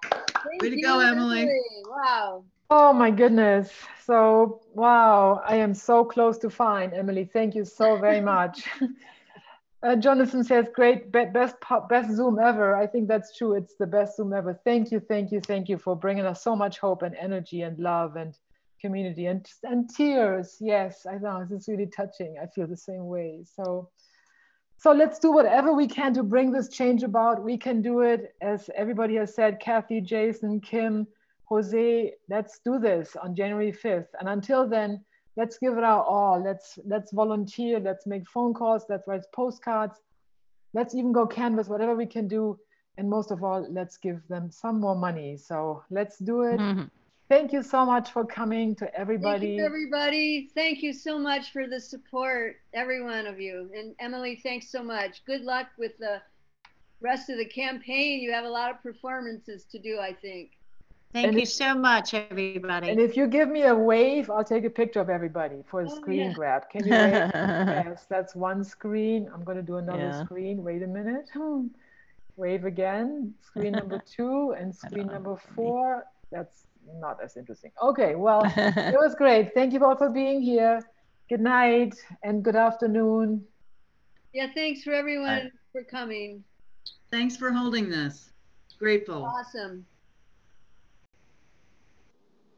(audio captured from zoon meeting)